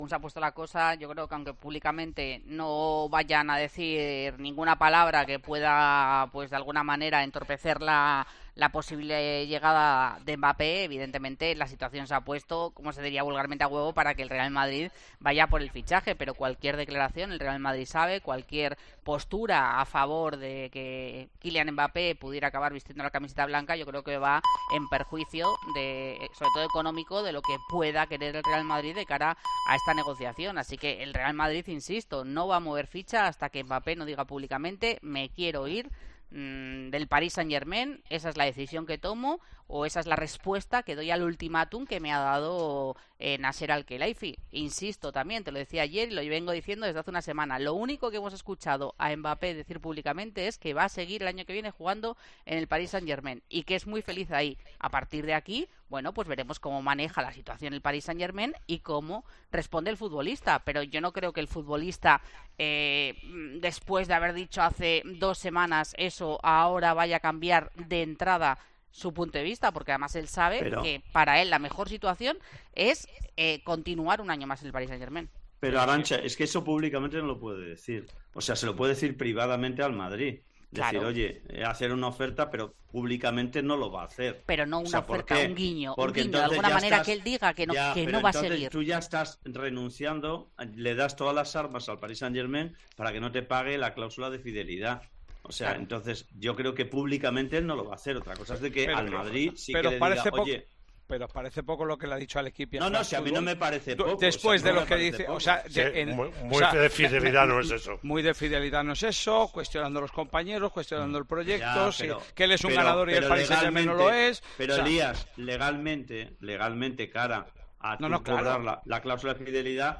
El Tertulión de Tiempo de Juego debate sobre las dudas con el fichaje de Kylian Mbappé por el Real Madrid